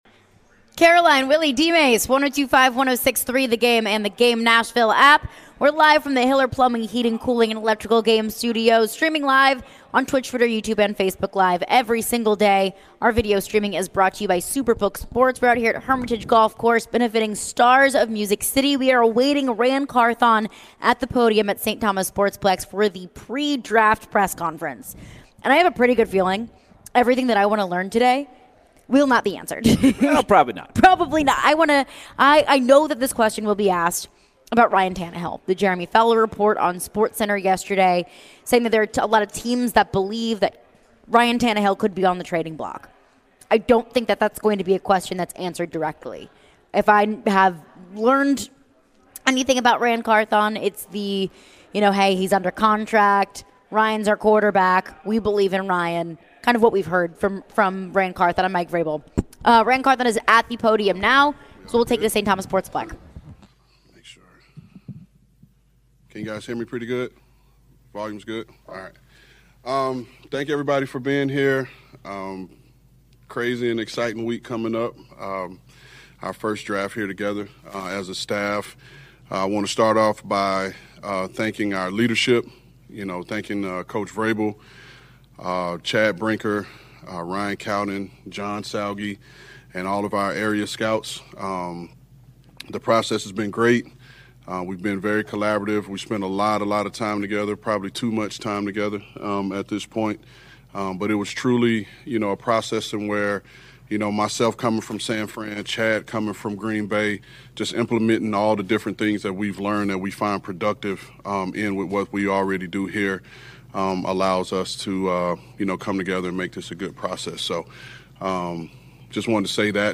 Tennessee Titans GM Ran Carthon spoke to the media today ahead of the 2023 NFL Draft on Thursday.